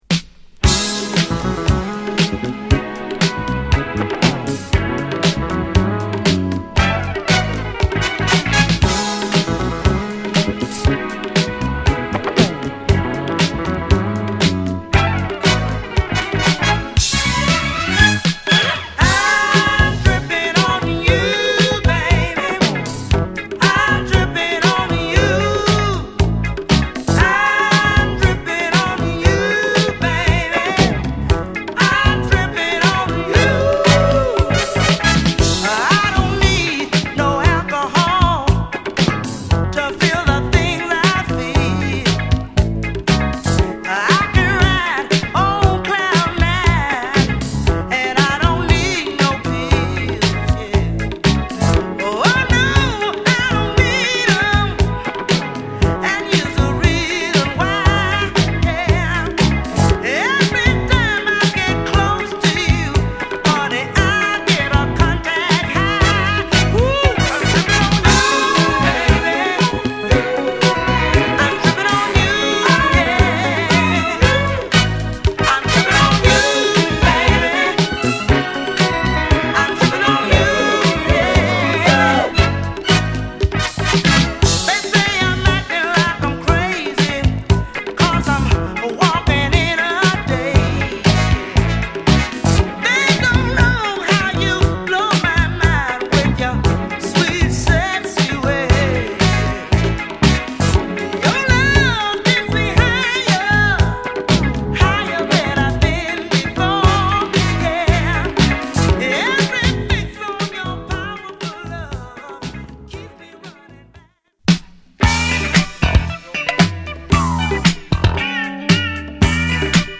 グッドなダンストラックを満載！
※シールドのため、試聴ファイルは別コピーからの録音です。